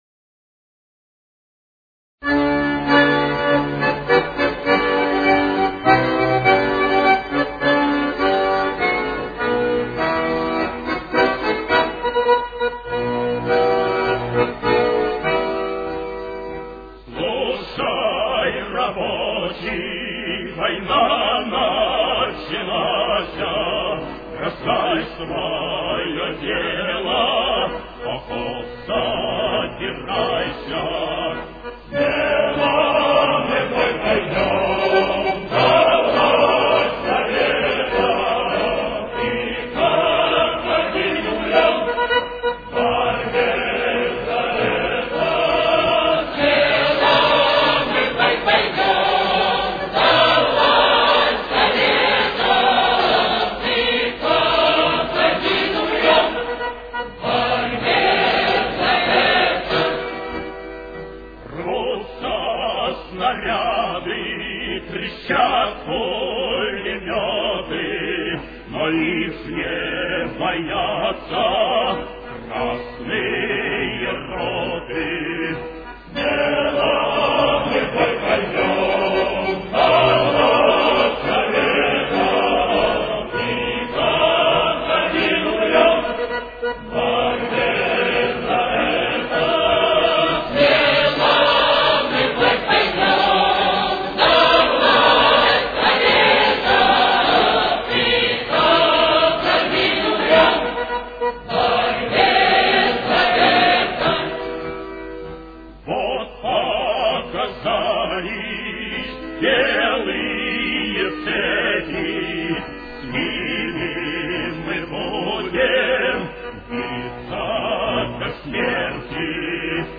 Соль минор. Темп: 103.